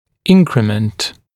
[‘ɪŋkrɪmənt][‘инкримэнт]прирост, увеличение, приращение, инкремент